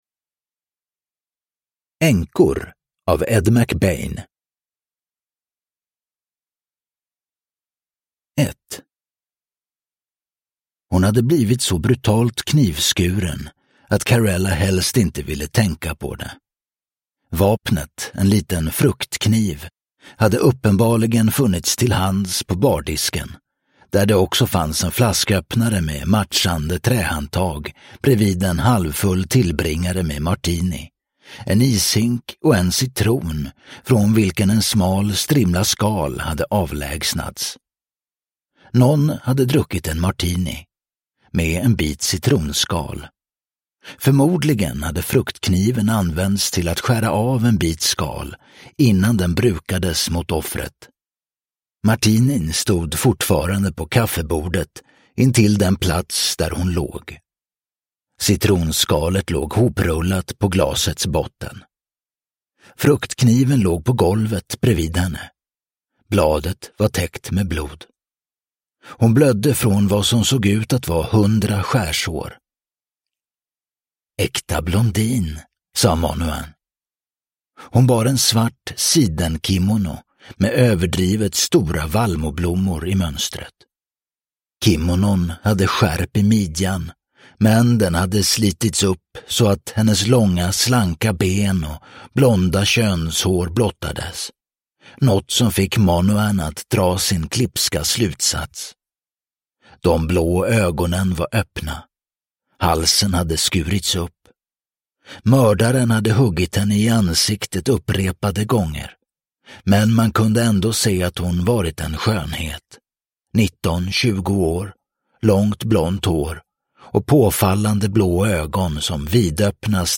Änkor – Ljudbok – Laddas ner